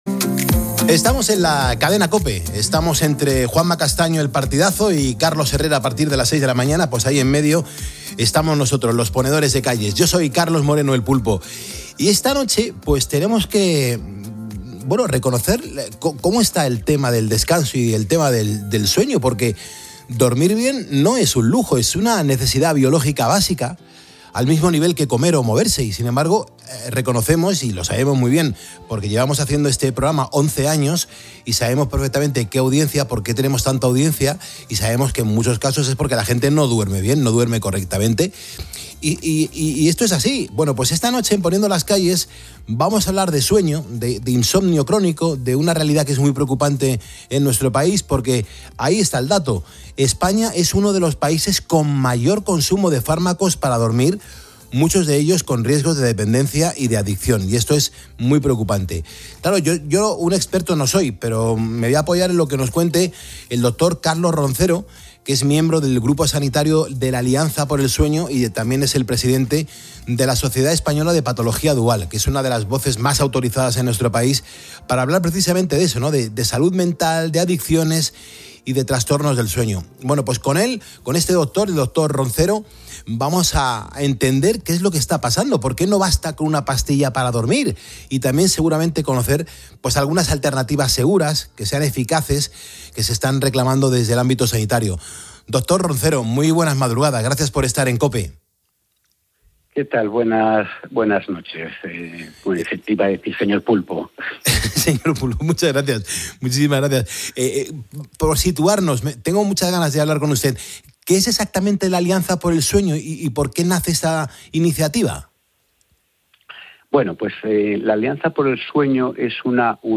en el programa 'Poniendo las Calles' , de la cadena COPE